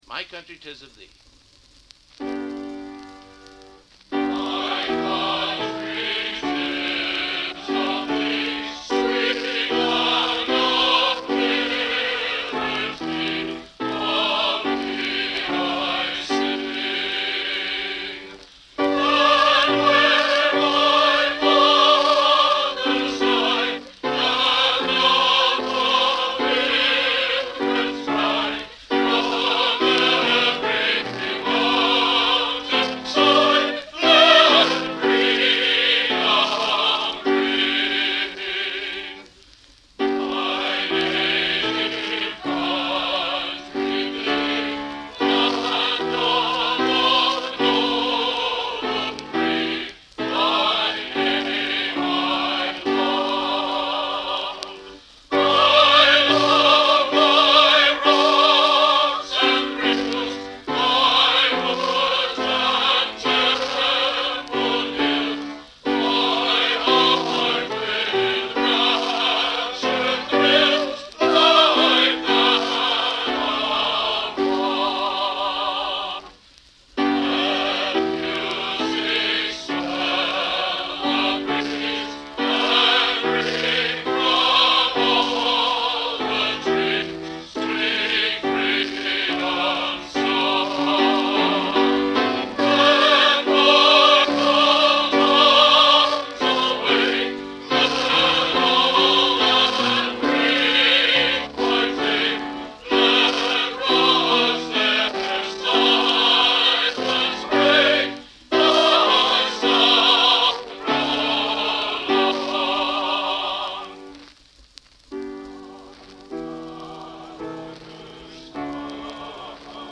Genre: Patriotic | Type: Studio Recording